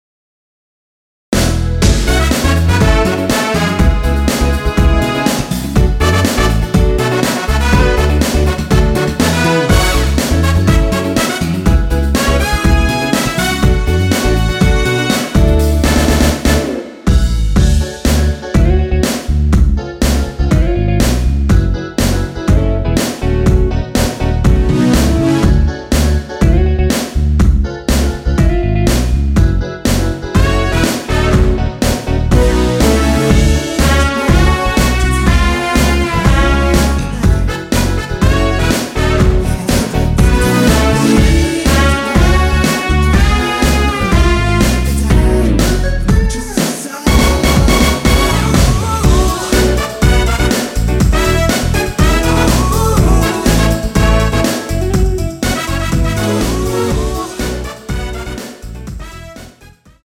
랩은 코러스가 아니라 포함되어있지 않습니다.
원키에서(-1)내린 코러스 포함된 MR입니다.
F#m
앞부분30초, 뒷부분30초씩 편집해서 올려 드리고 있습니다.
중간에 음이 끈어지고 다시 나오는 이유는